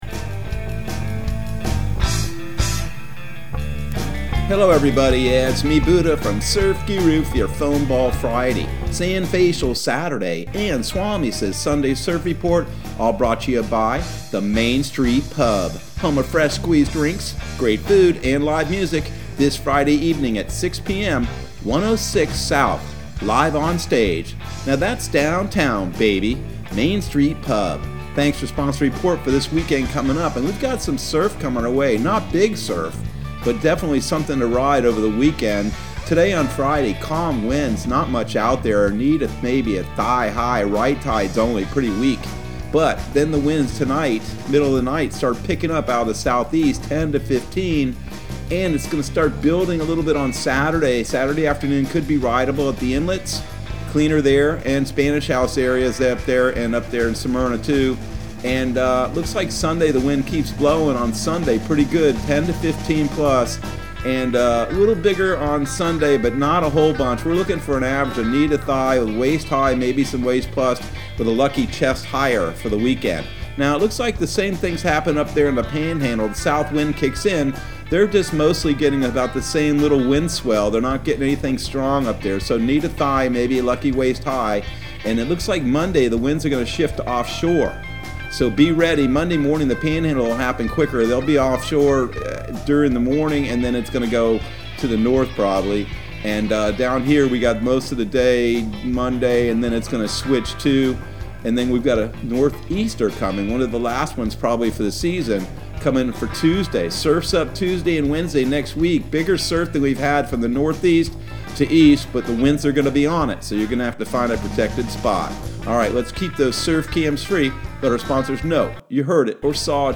Surf Guru Surf Report and Forecast 02/26/2021 Audio surf report and surf forecast on February 26 for Central Florida and the Southeast.